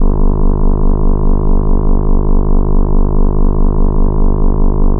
spaceEngineLow_002.ogg